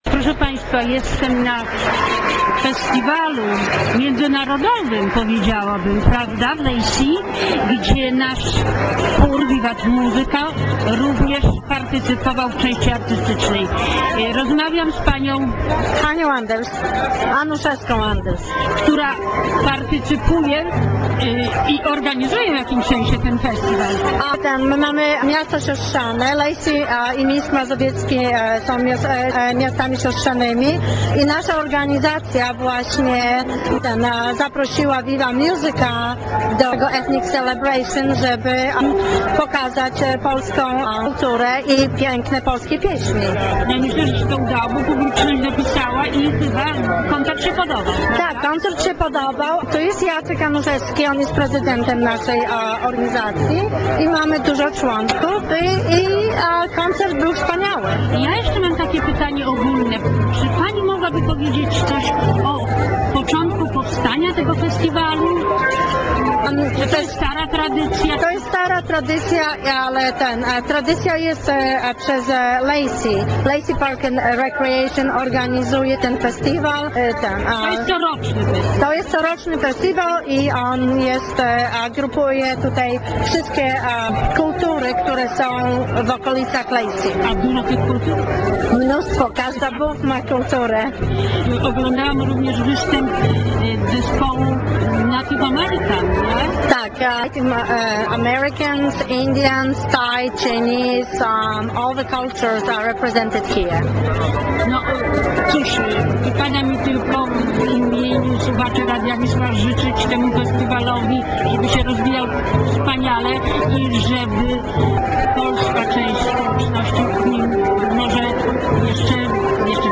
2 lutego 2013 roku w Lacey na Festiwalu Etnicznym wystąpił chór Vivat Musica z Seattle. Zapraszamy na reportaż z tego wydarzenia.